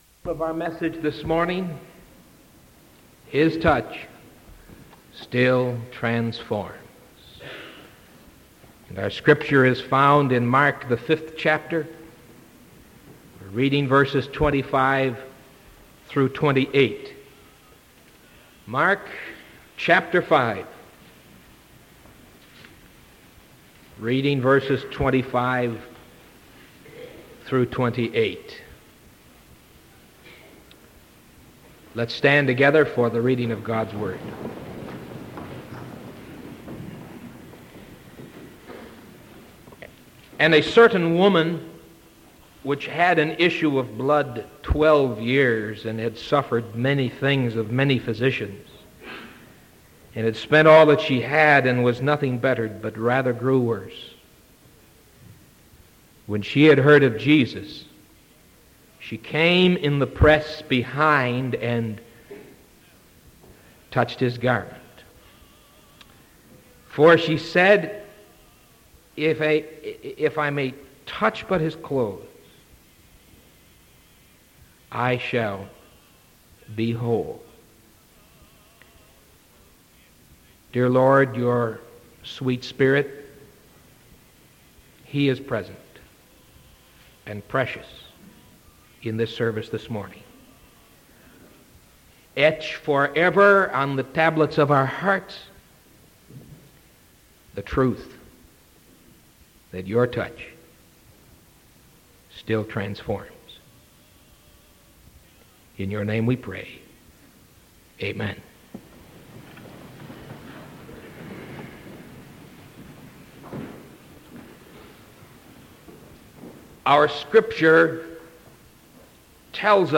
Sermon November 9th 1975 AM